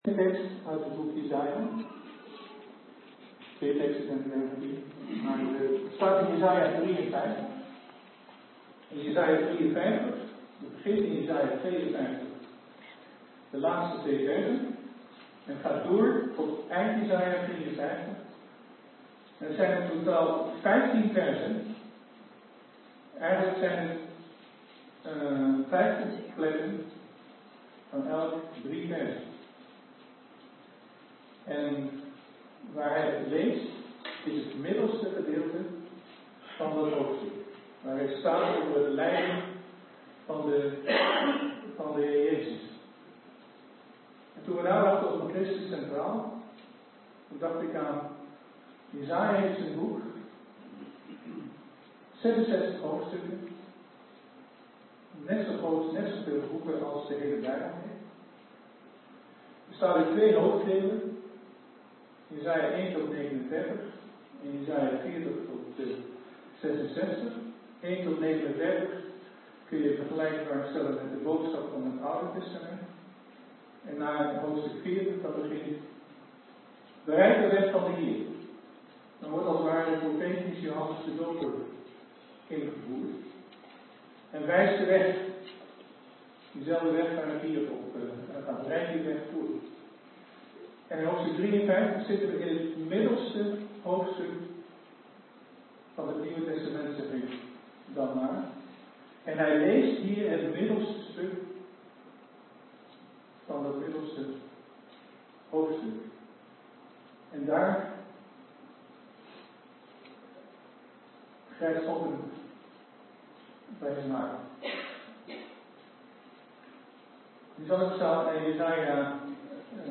De opname is niet goed gelukt, het begin van de toespraak mist